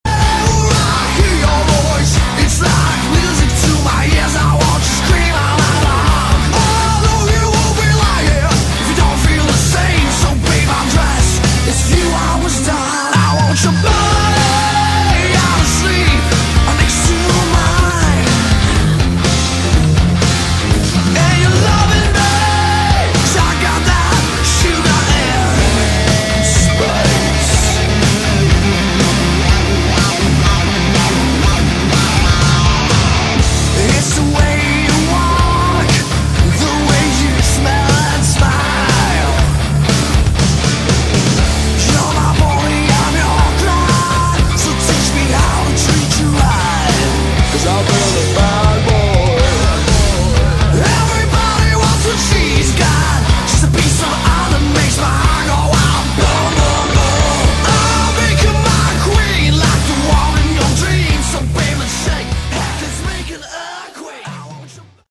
Category: Hard Rock
I like the more dangerous, youth gone wild sleazy edge.